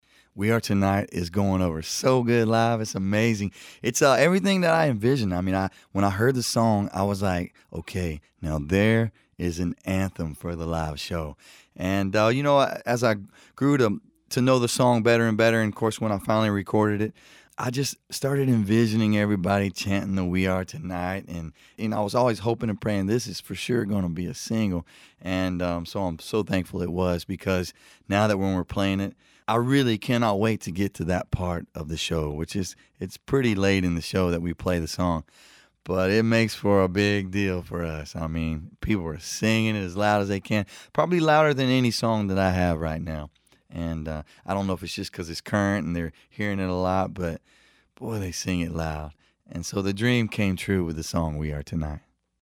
AUDIO: Billy Currington talks about his dreams for his latest single, “We Are Tonight.”